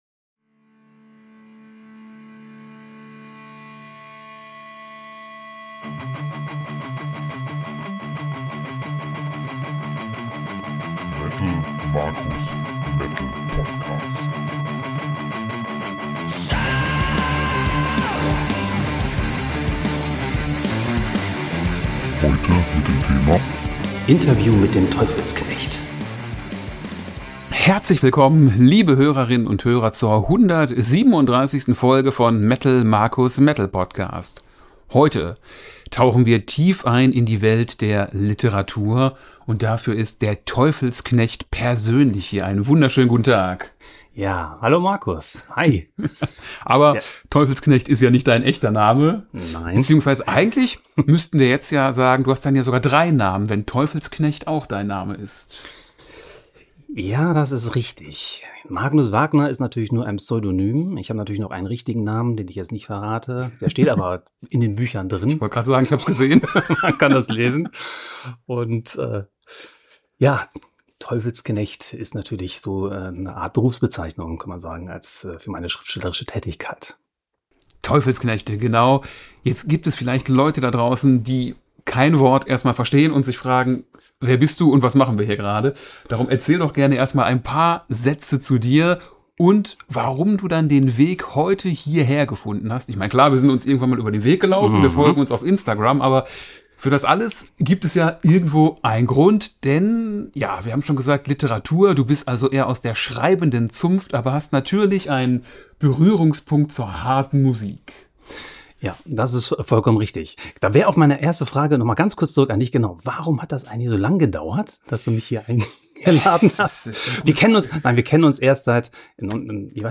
Er erzählt uns, wie er zum Schreiben kam, worum es in seinen Büchern geht und welche Vorteile das Self-Publishing mit sich bringt. Seid also gespannt auf das etwas andere Interview, bei dem es nicht immer zu 100 % nur um Musik geht.